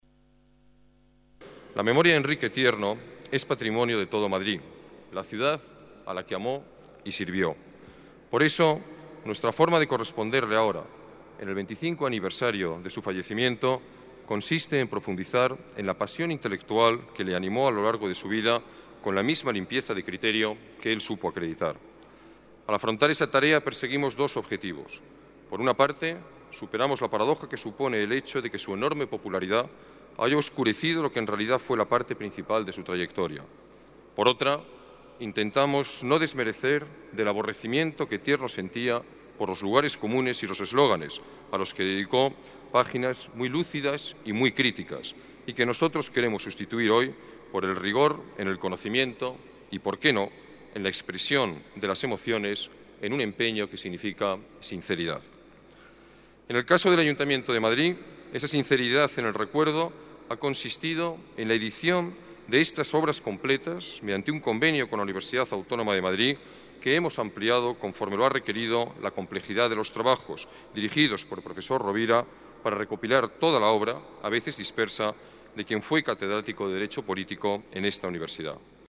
El alcalde presenta, en la Galería de Cristal del Ayuntamiento, las Obras Completas del Viejo Profesor